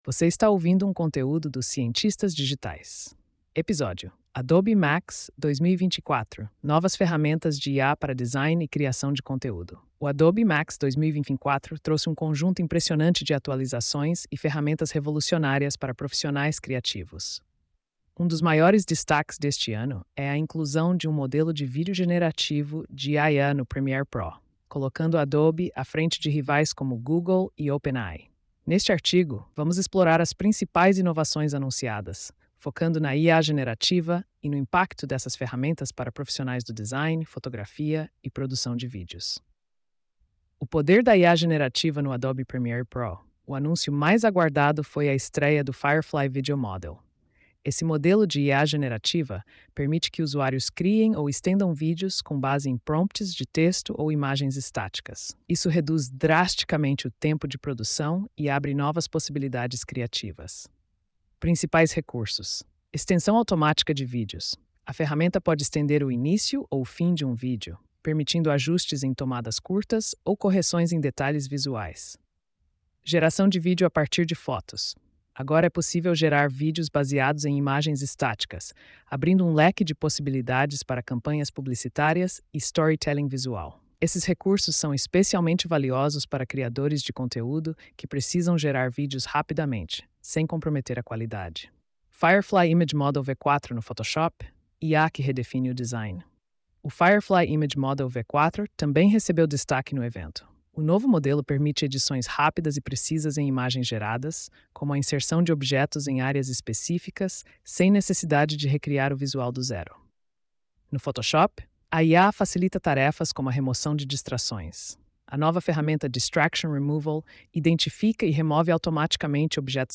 post-2569-tts.mp3